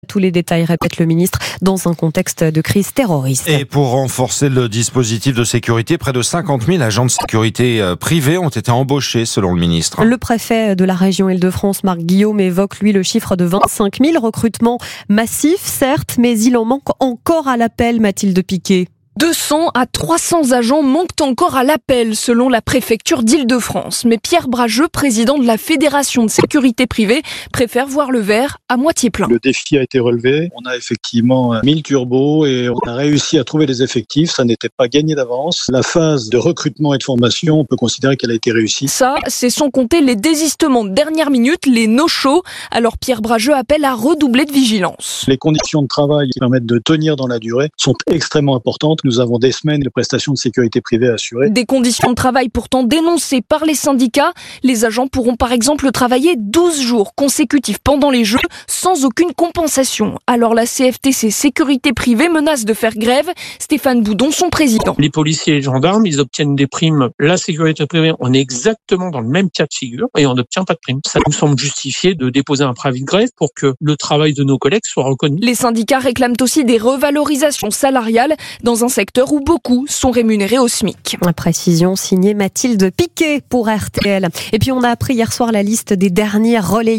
Néanmoins, quand les agents de police ou les gendarmes mobilisés bénéficieront de primes, les professionnels de la sécurité privée devront composer avec des conditions de travail particulièrement intenses, le plus souvent sans aucune forme de compensation. Des manques dénoncés sur les ondes de RTL ce mardi